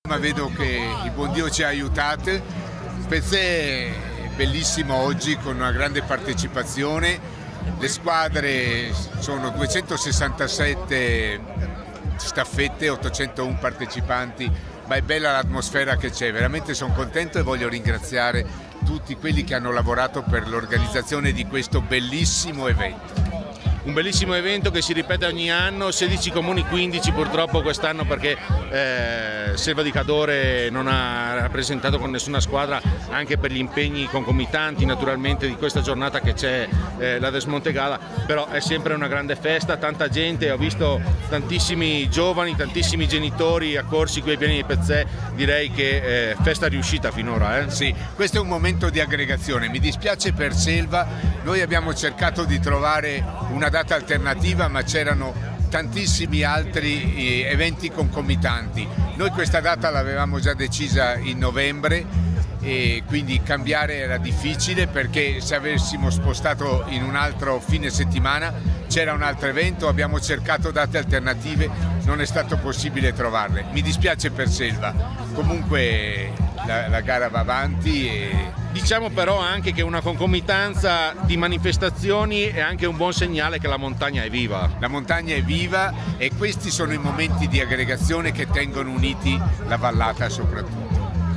AUDIO: IL SINDACO DI ALLEGHE DANILO DE TONI, “GRAZIE!”